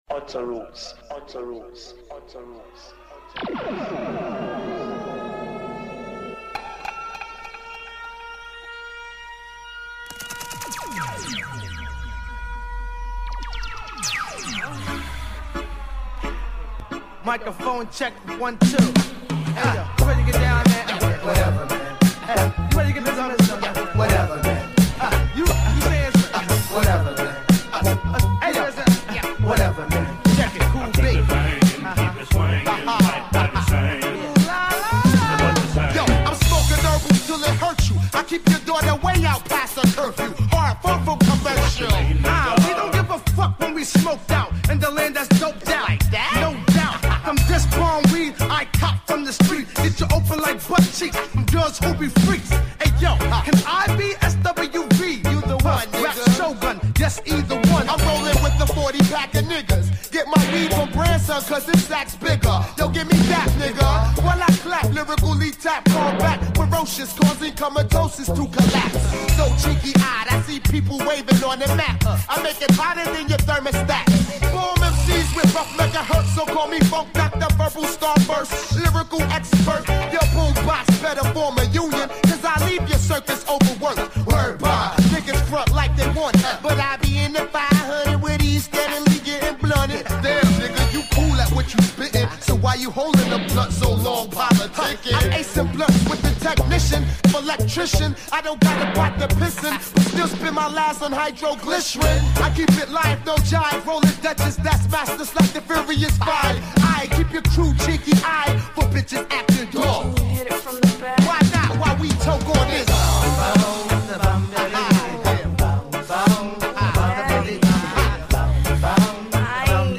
mix tape Reggae Hip Hop
Strictly Vinyl Selection